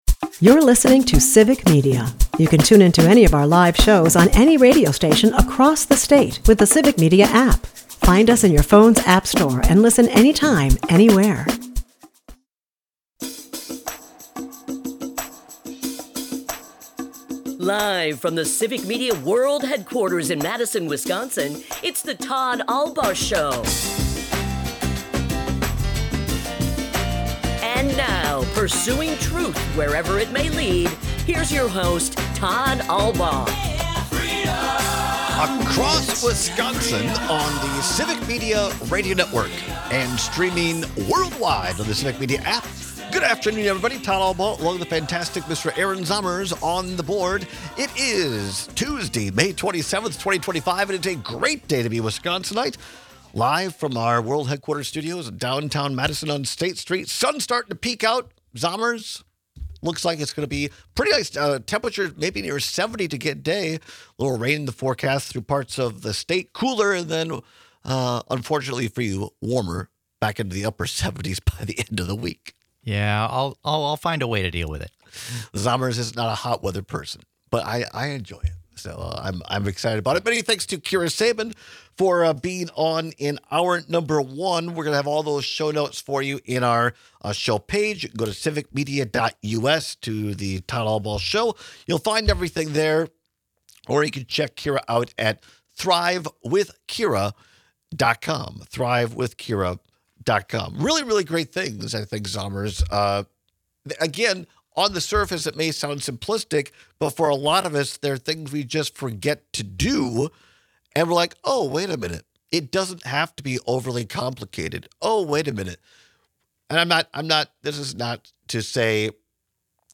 Both of Green Bay’s most recent MVP quarterbacks have less-than-stellar reputations. We take your calls and texts on which is worse.&nbsp